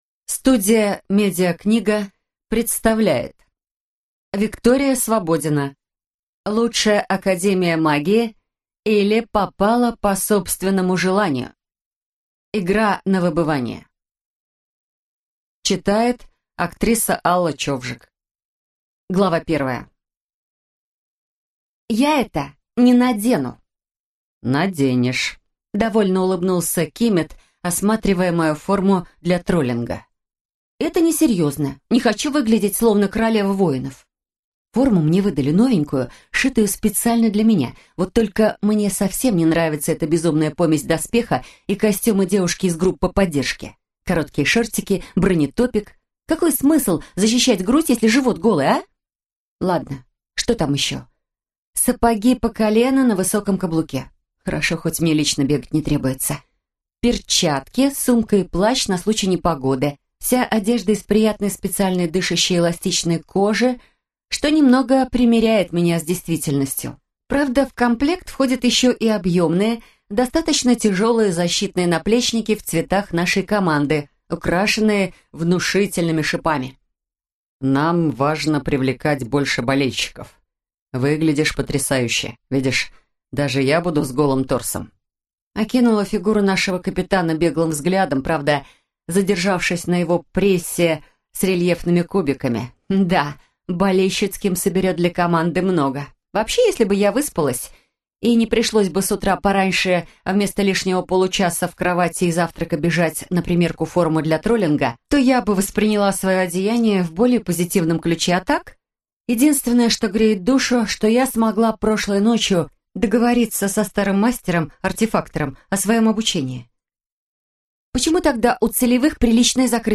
Аудиокнига Лучшая Академия магии, или Попала по собственному желанию 2. Игра на выбывание | Библиотека аудиокниг